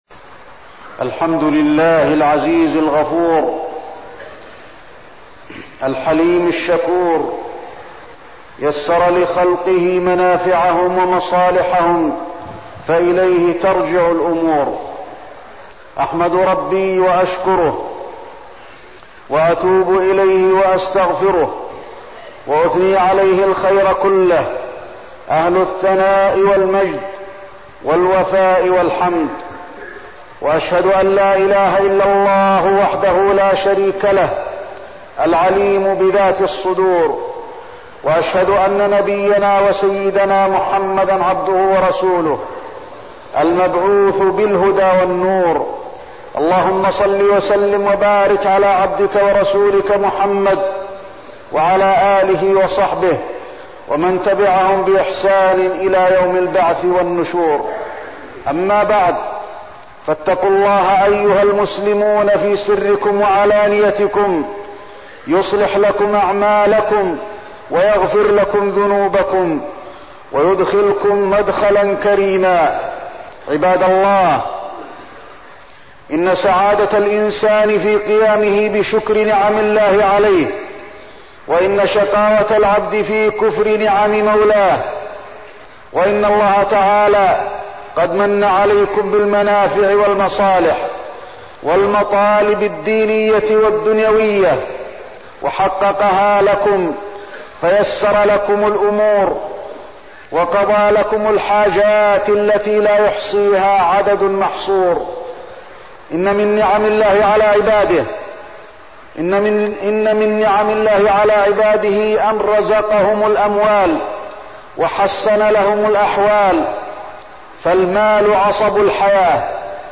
تاريخ النشر ١ جمادى الآخرة ١٤١٥ هـ المكان: المسجد النبوي الشيخ: فضيلة الشيخ د. علي بن عبدالرحمن الحذيفي فضيلة الشيخ د. علي بن عبدالرحمن الحذيفي إنفاق المال